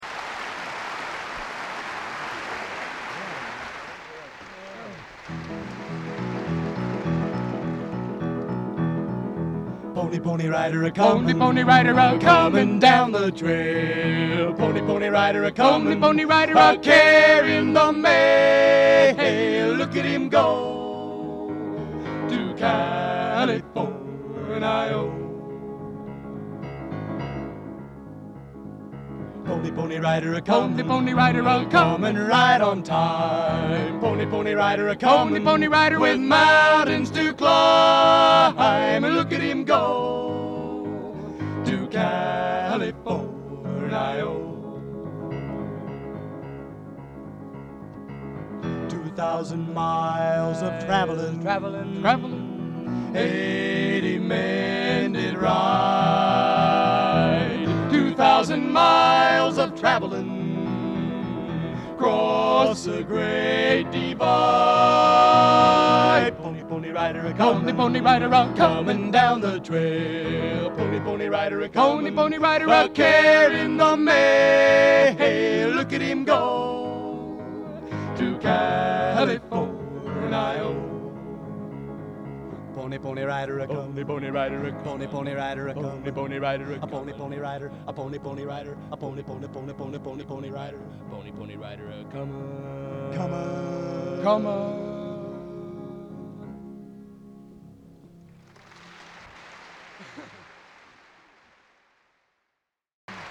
Genre: Original Composition | Type: Featuring Hall of Famer